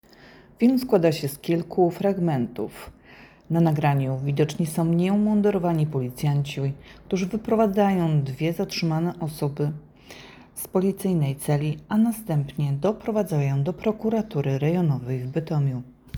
Nagranie audio Audiodeskrypcja filmu